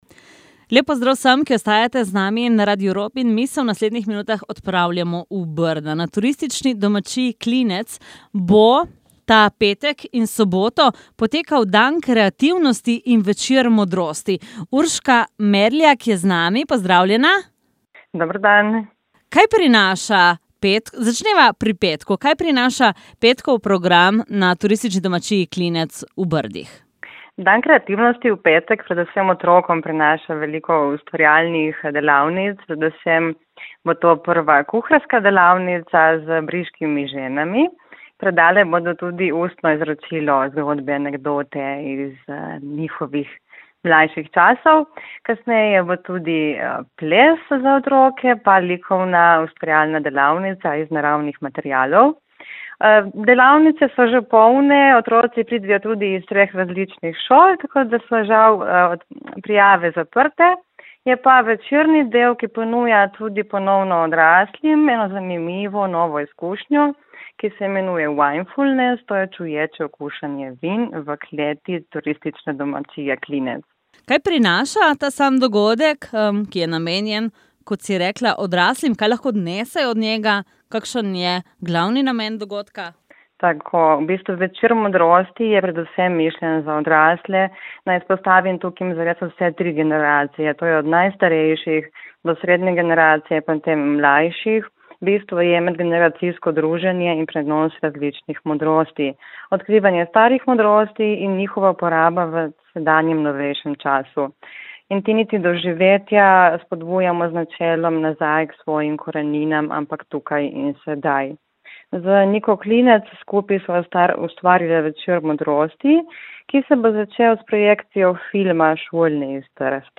Intervju